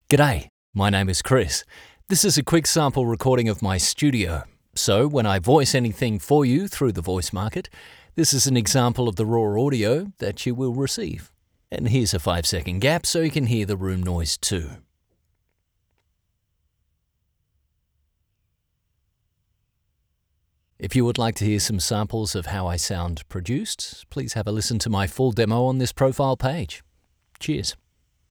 Award-winning Australian Voice. versatile, clear, and seriously experienced.
• Studio Sound Check
• Professional Voice booth – acoustically treated.